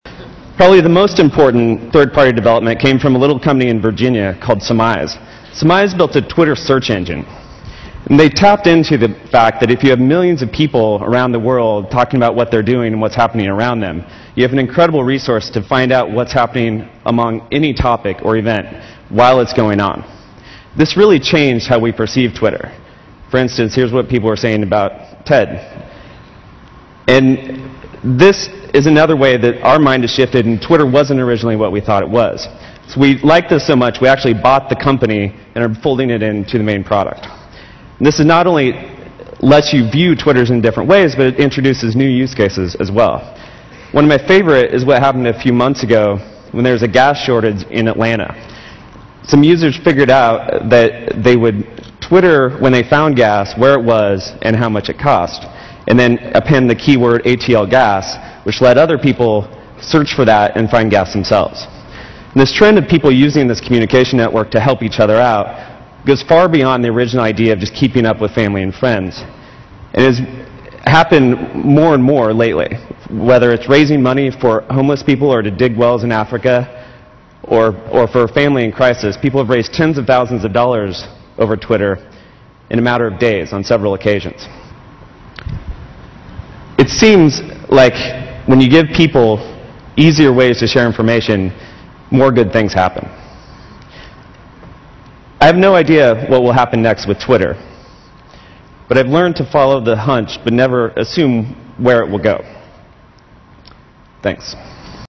财富精英励志演讲69：分享生活点滴(4) 听力文件下载—在线英语听力室